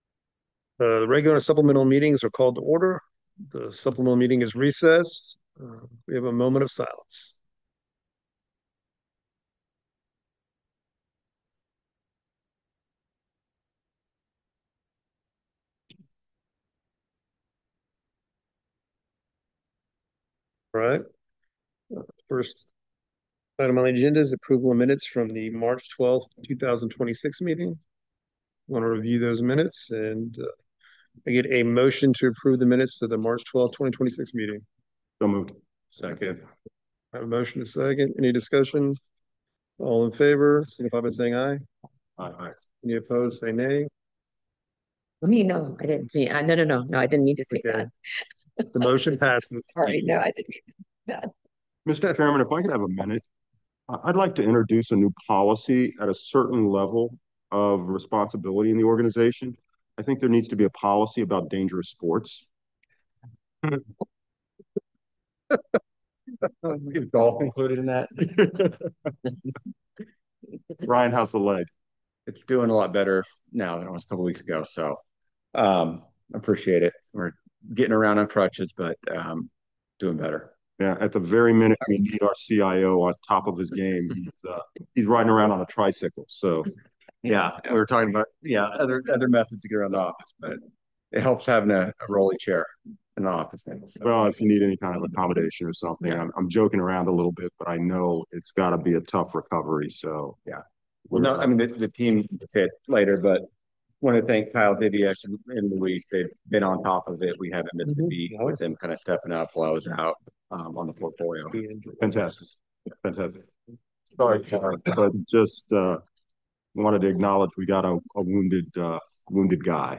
Teleconference Audio 1